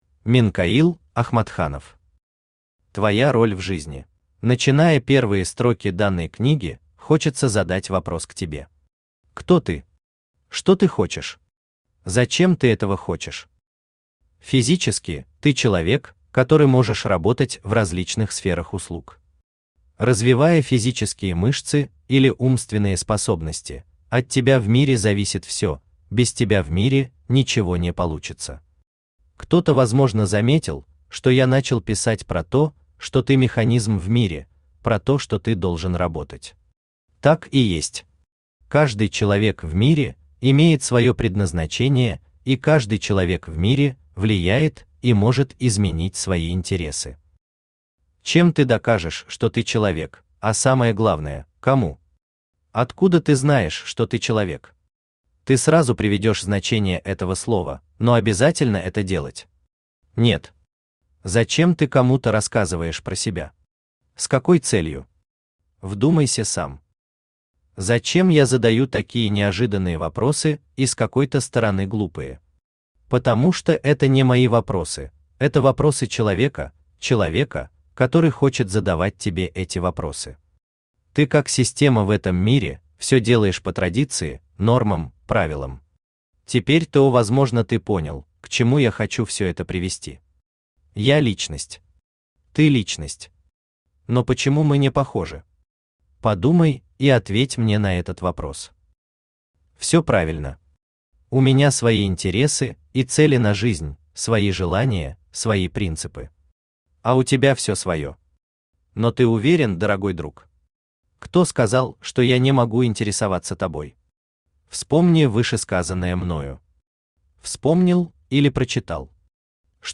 Аудиокнига Твоя Роль В Жизни | Библиотека аудиокниг
Aудиокнига Твоя Роль В Жизни Автор Минкаил Рустамович Ахматханов Читает аудиокнигу Авточтец ЛитРес.